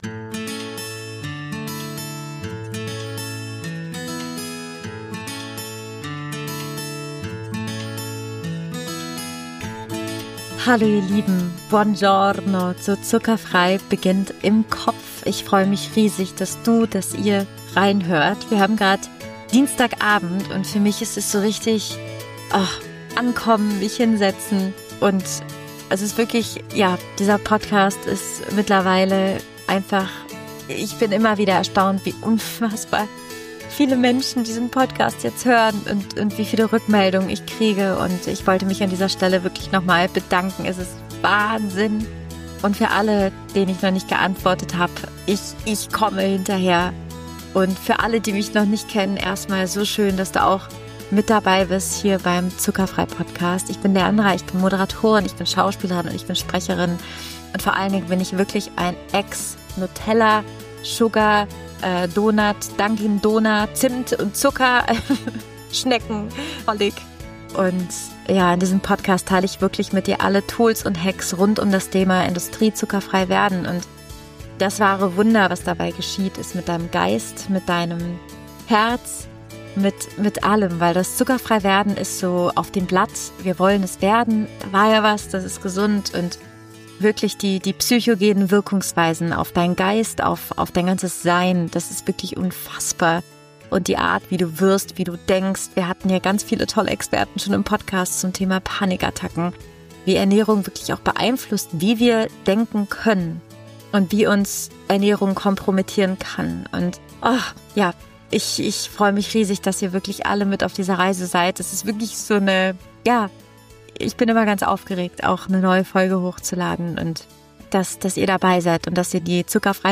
Wie deine Seele durch den Körper zu dir spricht – Interview